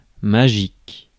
Ääntäminen
Ääntäminen France: IPA: /ma.ʒik/ Haettu sana löytyi näillä lähdekielillä: ranska Käännös Adjektiivit 1. mágico Suku: f .